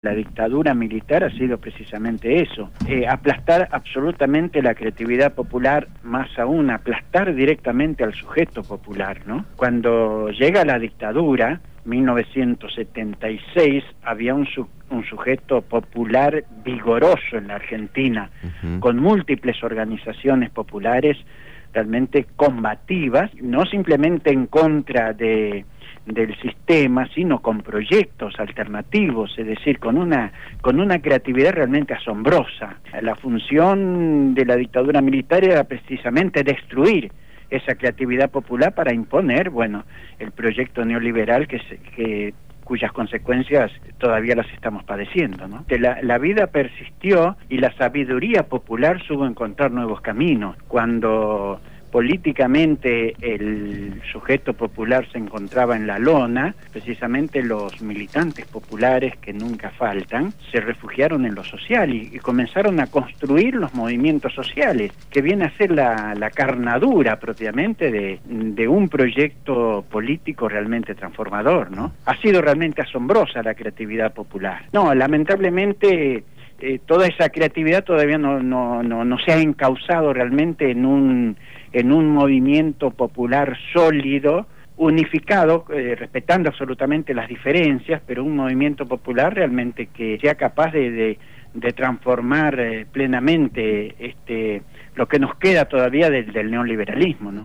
fue entrevistado